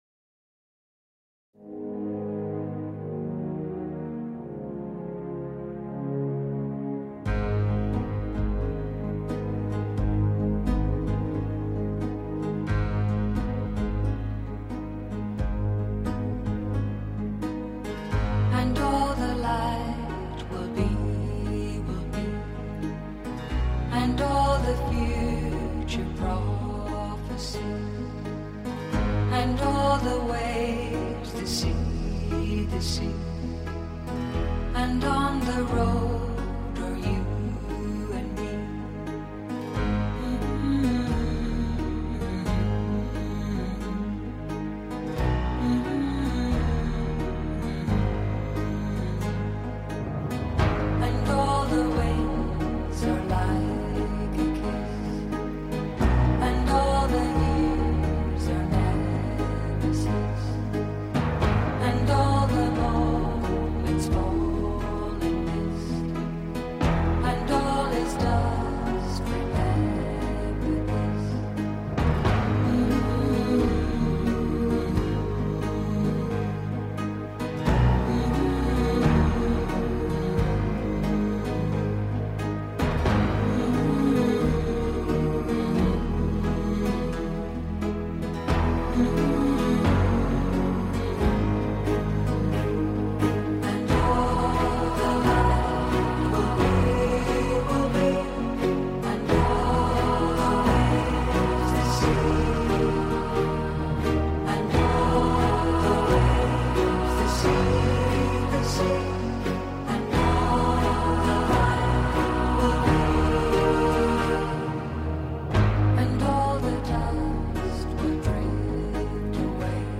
Just her voice, layered into choirs, suggests sanctity.